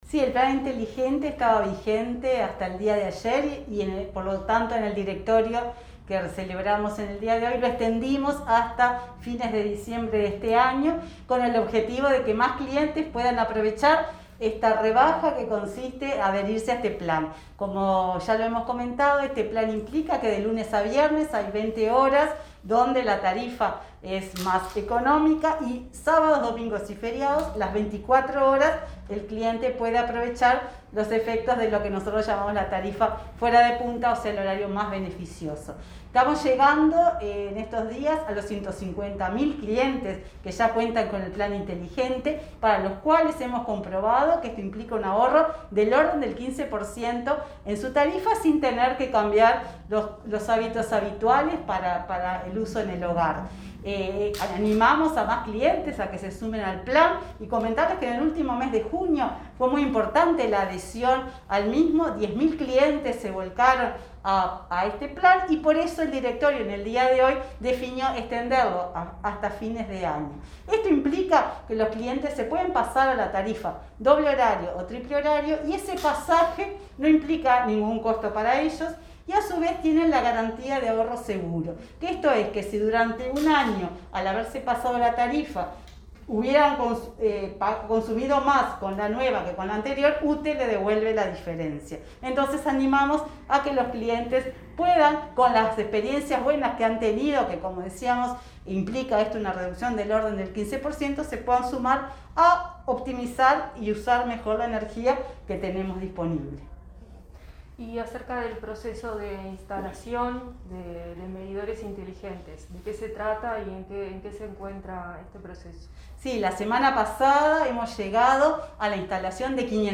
Palabras de Silvia Emaldi, presidenta de UTE
Palabras de Silvia Emaldi, presidenta de UTE 01/07/2021 Compartir Facebook X Copiar enlace WhatsApp LinkedIn En diálogo con Comunicación Presidencial, este jueves 1.º, la presidenta de la Administración Nacional de Usinas y Trasmisiones (UTE), Silvia Emaldi, informó acerca de la extensión del Plan Inteligente y del plan de instalación de medidores inteligentes.